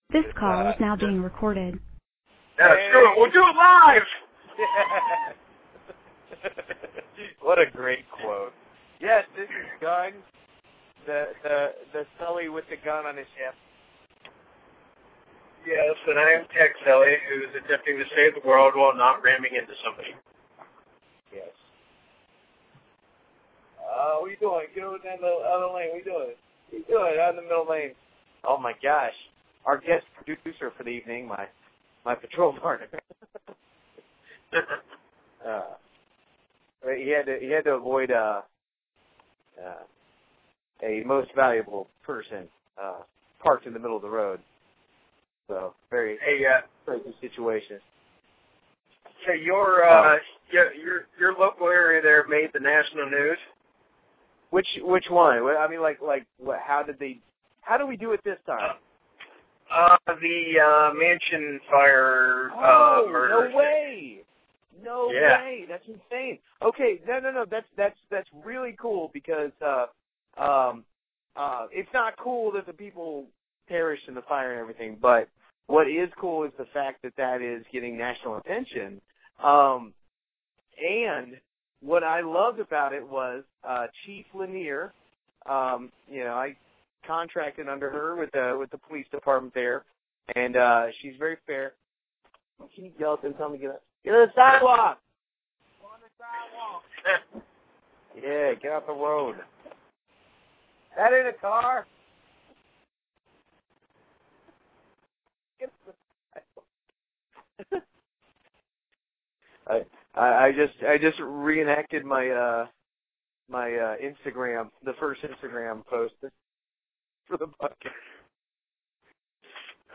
The brothers also discuss how to disagree without ending communication.